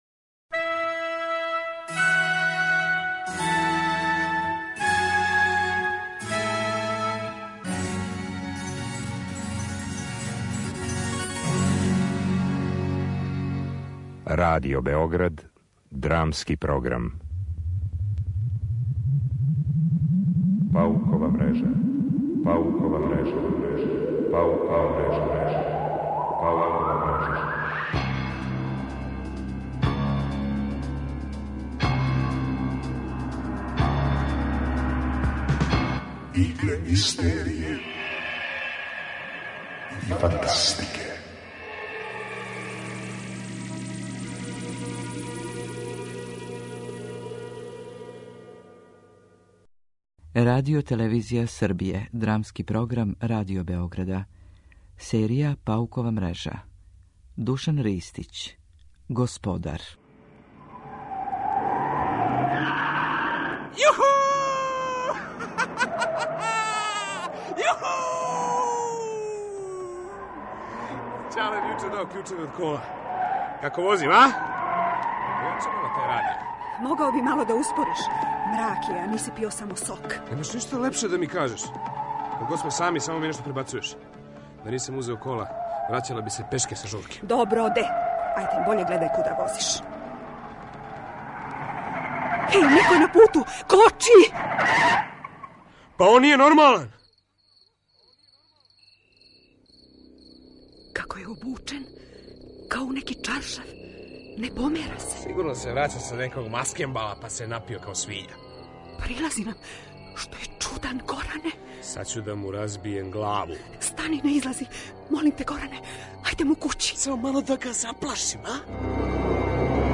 Драмски програм: Паукова мрежа